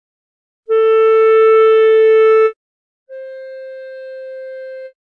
• il primo suono risulta forte;
• il secondo debole.